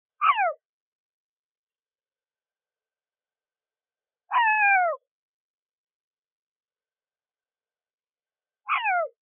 コミミズク｜日本の鳥百科｜サントリーの愛鳥活動